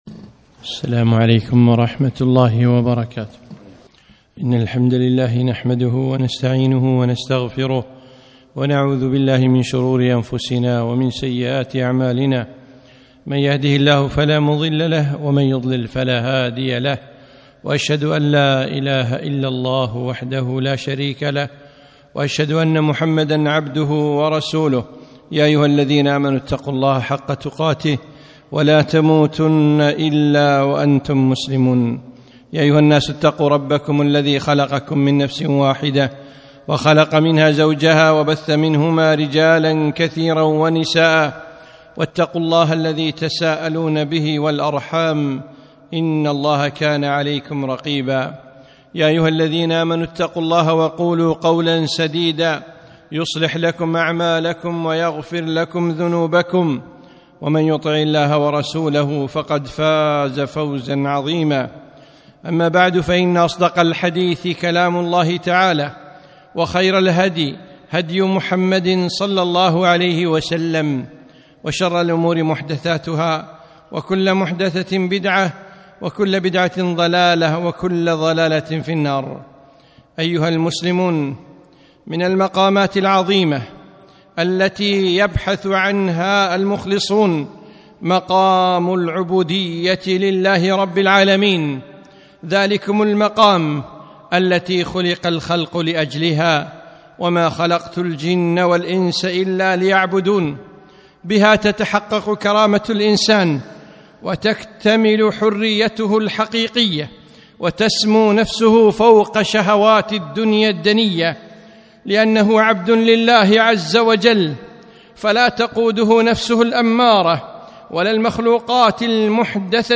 خطبة - العبودية لله تعالى عز وجل